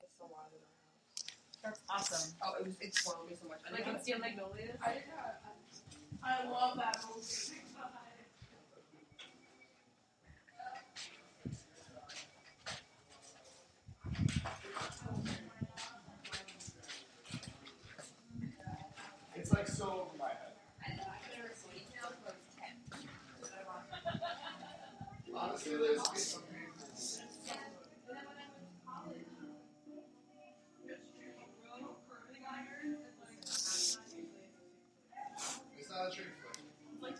Field Recording Number Six
Location: Lowe 108, lounge, Hofstra University
Sounds Heard: feet shuffling, piano, copier making copies, conversation.